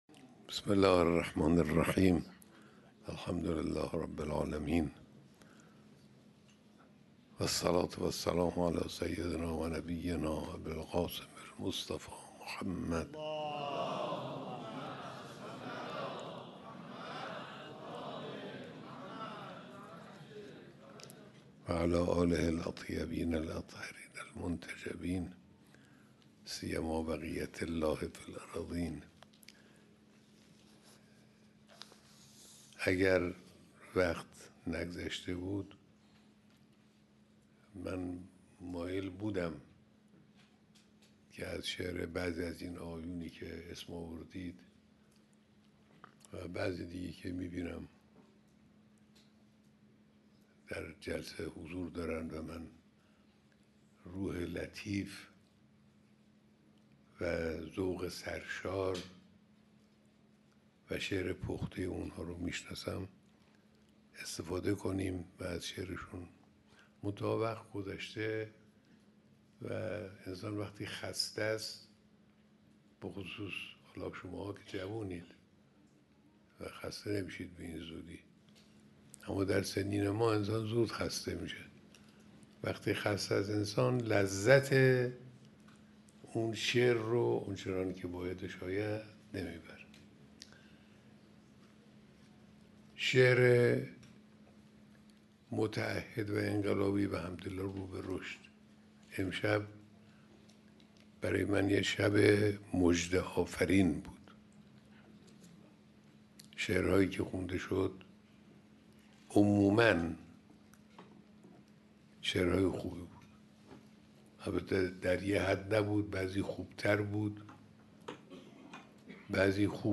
بیانات در دیدار با جمعی از شاعران جوان و پیشکسوت